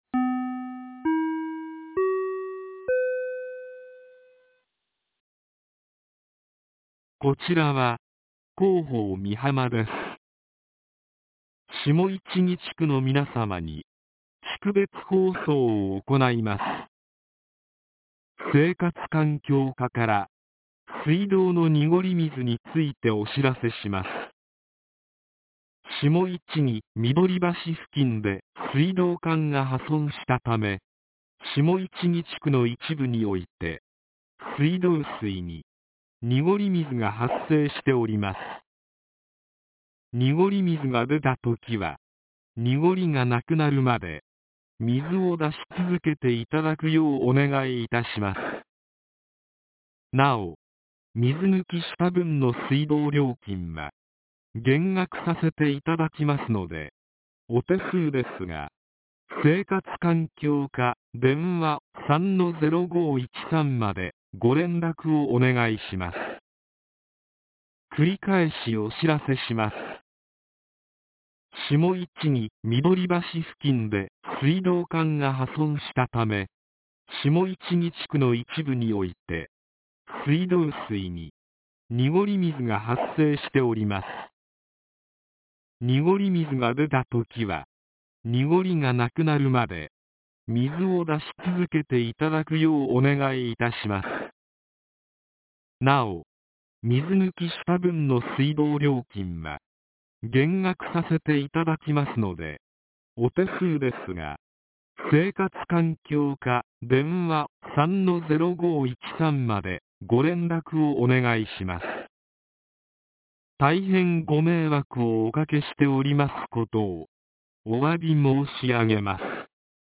■防災行政無線情報■
放送内容は下記の通りです。